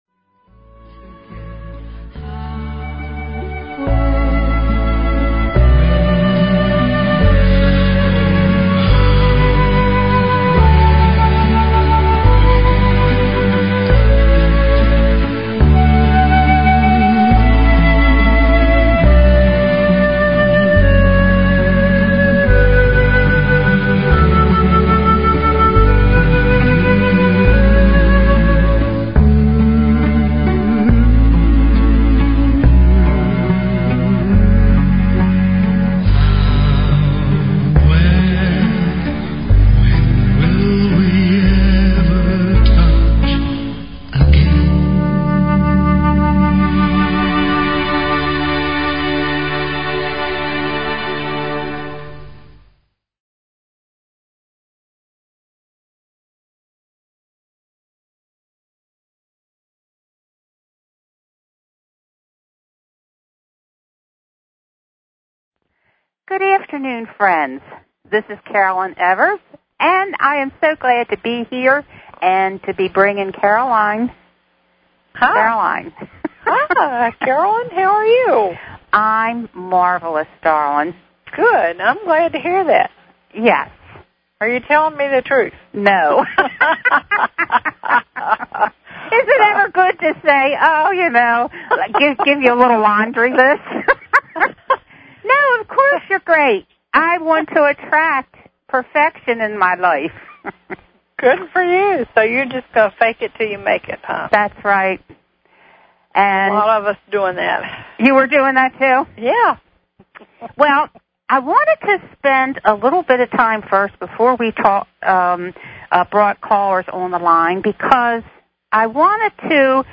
What I attempt to do in this series is to use the messages that I have received from the Spiritual Hierarchy, along with my own personal past life memories, and weave this information with current events, news stories and scientific confirmation. There will be times when I will read from the Earth records to give a deeper understanding of planetary history and current events.